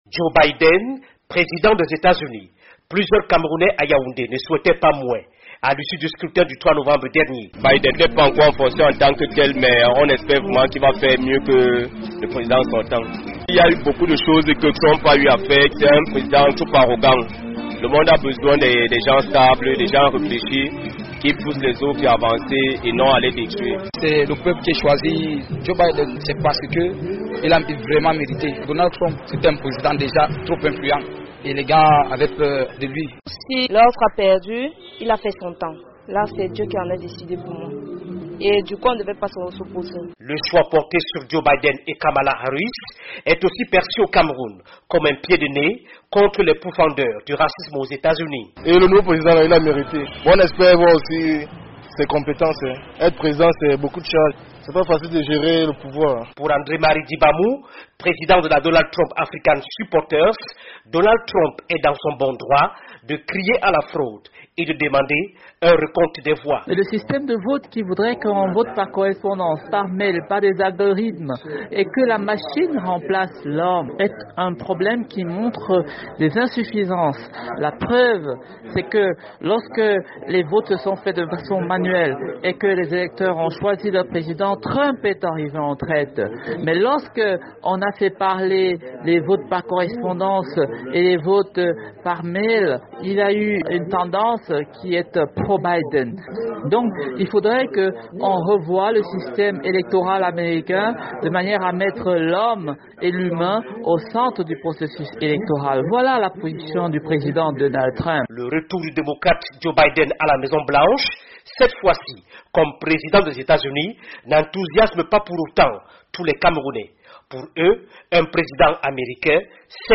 "Biden, c’est vrai qu’il n’est pas encore en fonction, mais on espère vraiment qu'il va faire mieux que Trump", soutient un jeune vendeur de téléphone à Yaoundé.